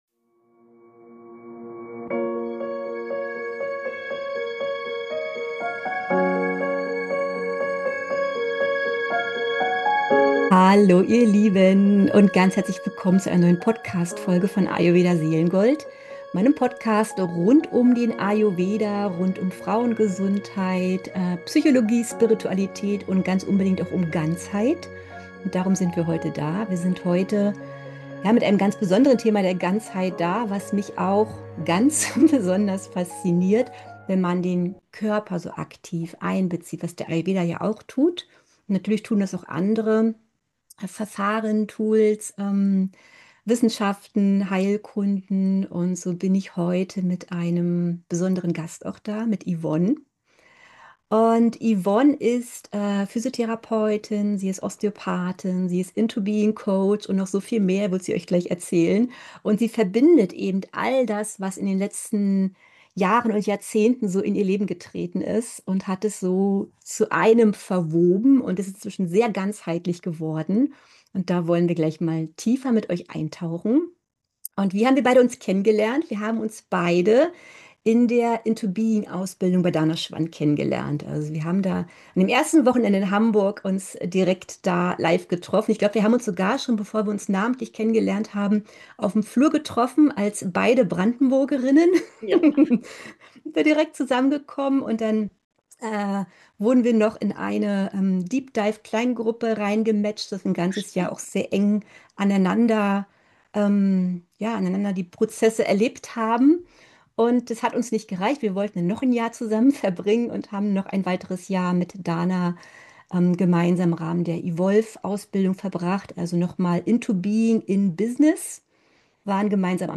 Berühren. Begegnen. Bewusstwerden. Ganzheitliche Osteopathie – im Gespräch